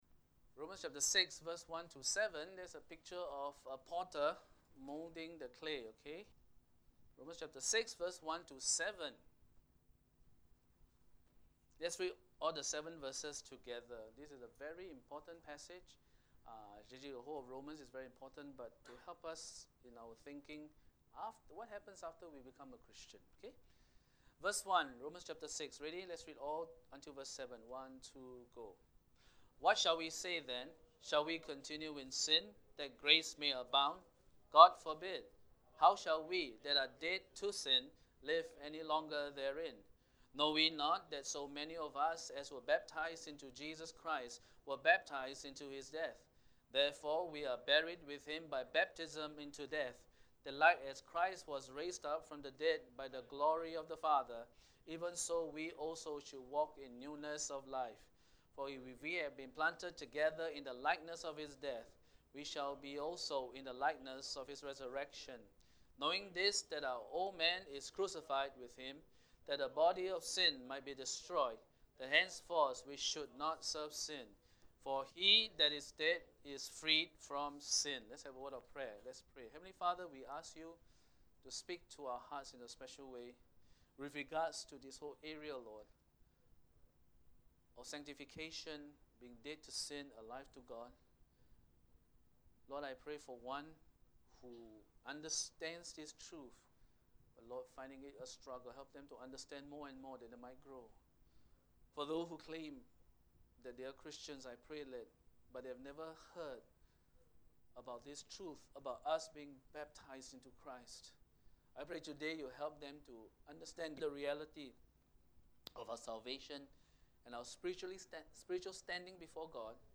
Sunday Worship Service 030618 What happens after we become a Christian? What does it mean to be baptised in Christ?